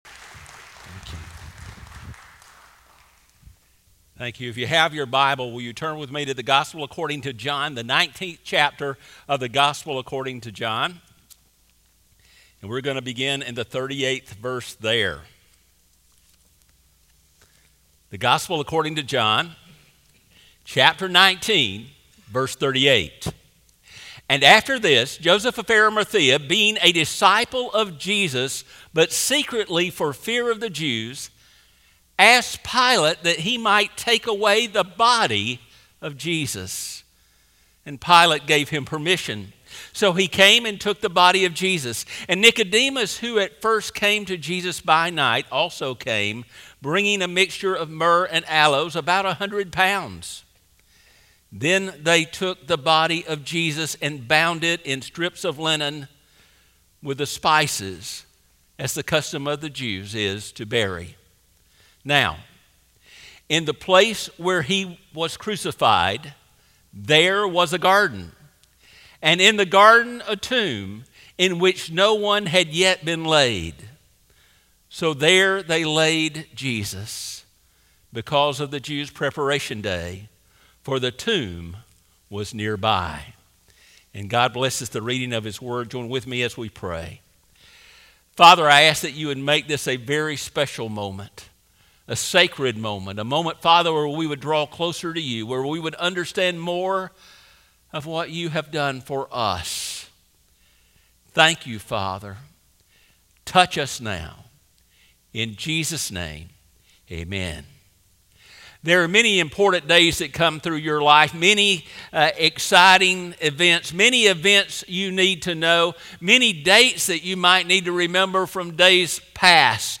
Sermons | Northside Baptist Church